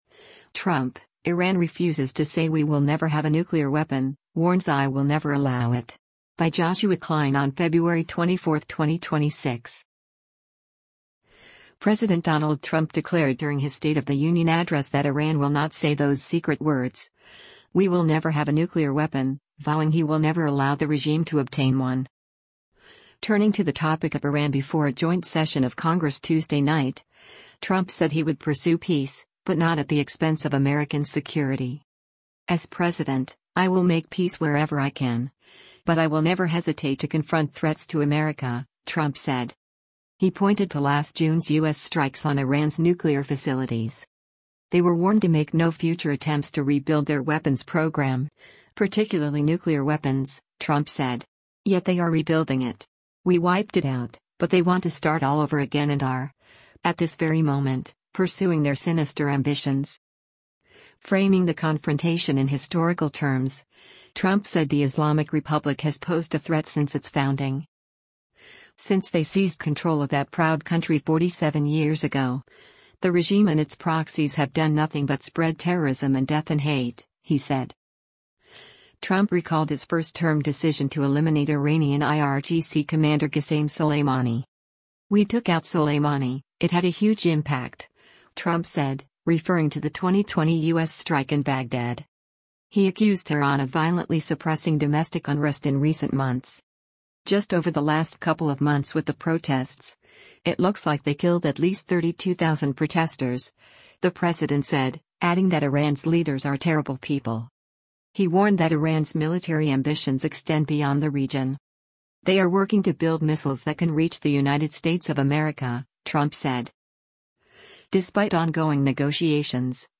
President Donald Trump declared during his State of the Union address that Iran will not say “those secret words: ‘We will never have a nuclear weapon,’” vowing he “will never allow” the regime to obtain one.
Turning to the topic of Iran before a joint session of Congress Tuesday night, Trump said he would pursue peace — but not at the expense of American security.